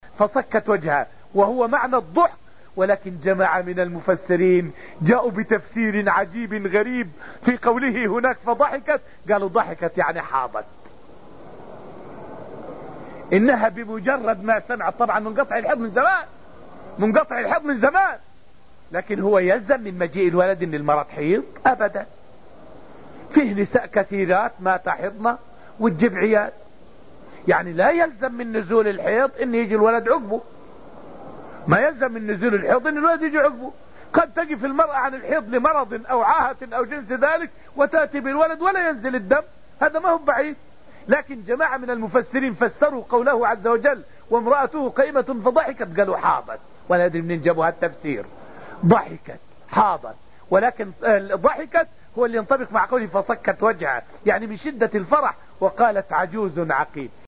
فائدة من الدرس السابع من دروس تفسير سورة الذاريات والتي ألقيت في المسجد النبوي الشريف حول فضل السجود لله وتحريم النار أن تأكل أثر السجود من بني آدم.